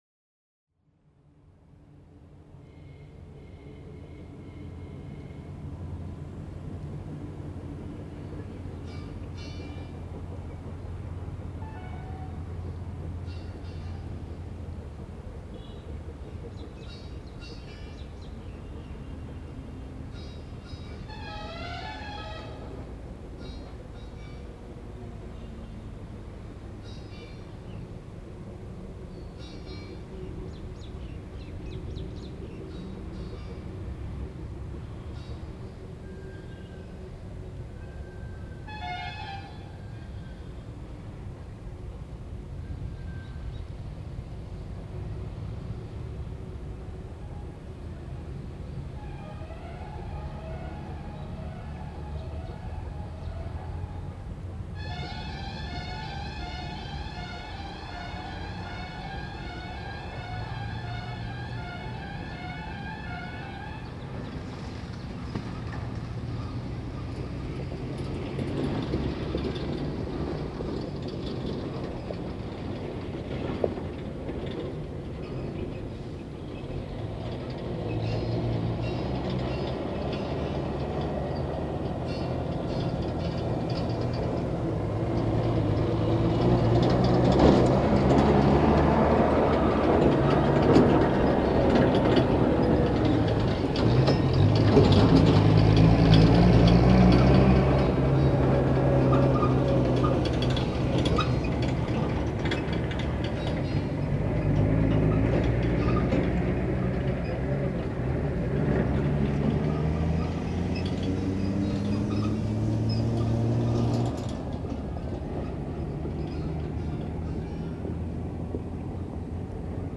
This is an anthology from the project ‘Situations and Circumstances’ — an ongoing series that studies seemingly mundane auditory situations by observing their spatial, temporal and quasi-musical developments. The project utilizes the methodology of uninterrupted, unedited and unprocessed field recording in search of the hint of a story or developing narrative that eventually unfolds in time.
Field Recording Series by Gruenrekorder
afternoon_on_the_field.mp3